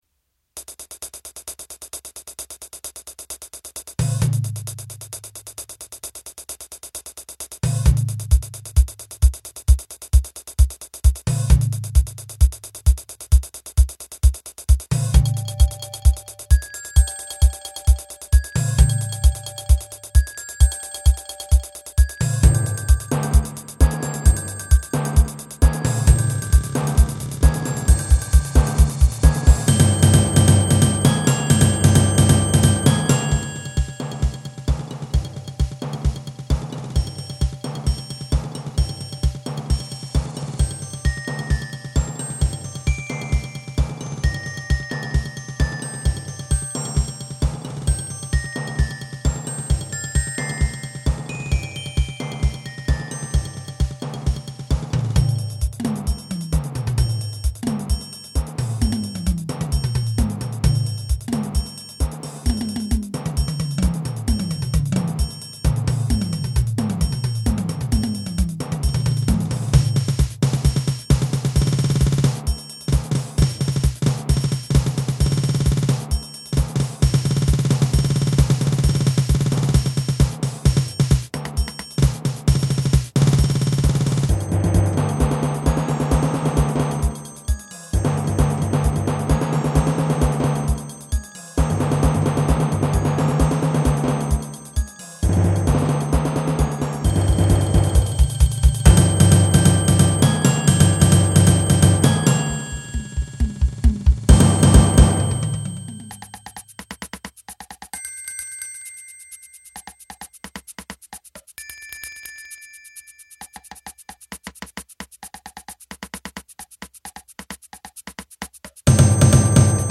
for eight percussionists
• Personnel: 8 players